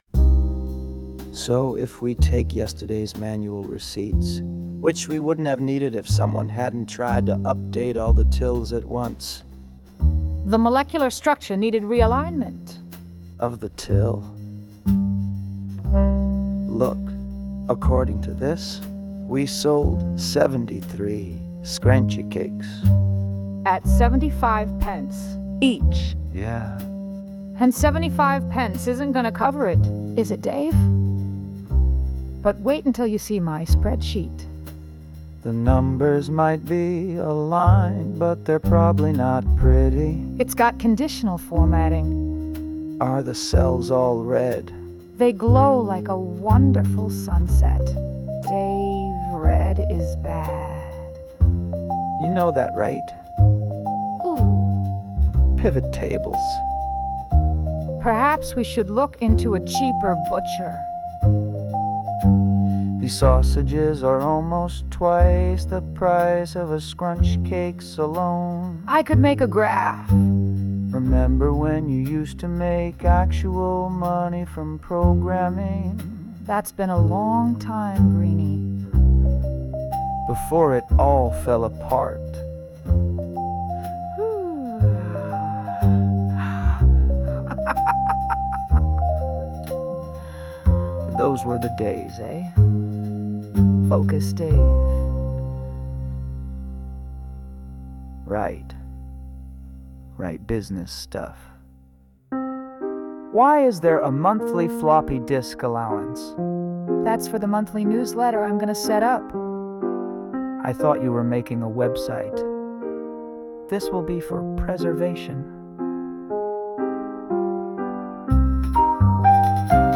Sound Imported : Xenial Dancing
Sung by Suno